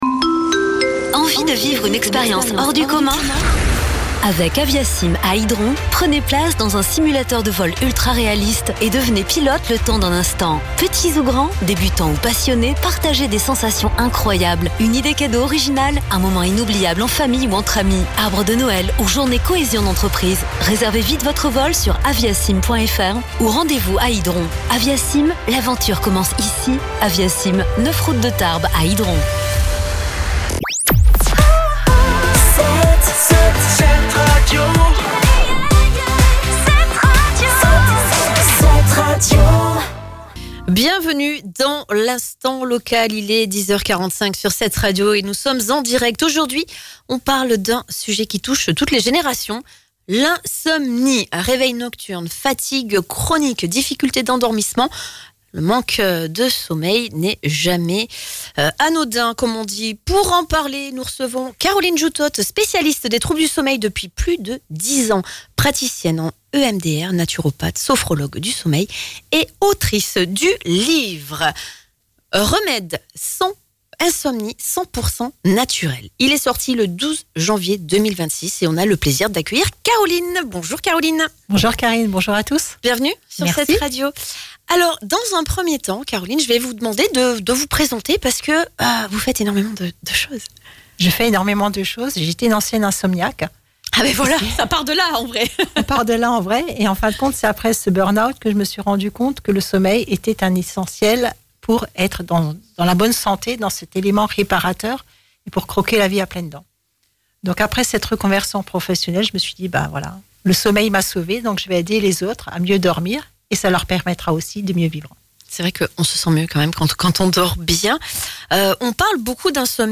Un échange riche en conseils et en pistes concrètes pour retrouver un meilleur sommeil… mais avant toute chose, il est essentiel de comprendre les cycles du sommeil avant d’agir. L’objectif : mieux dormir, tout simplement, et ainsi profiter pleinement de ses journées.